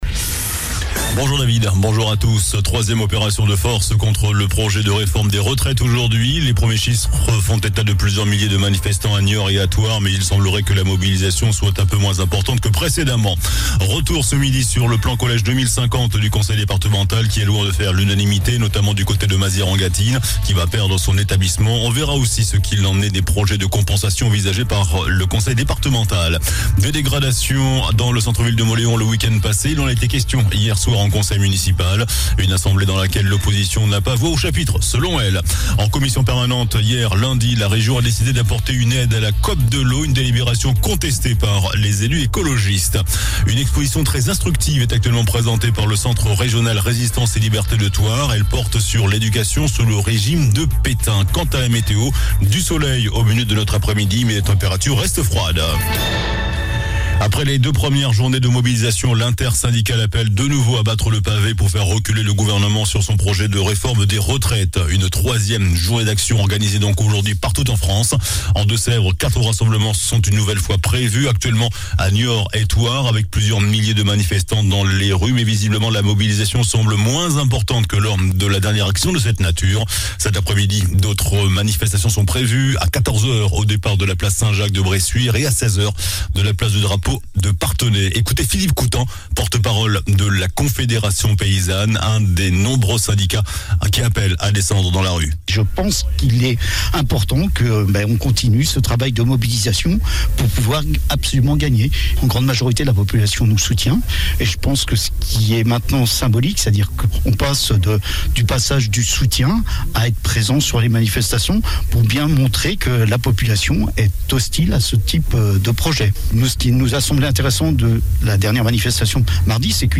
JOURNAL DU MARDI 07 FEVRIER ( MIDI )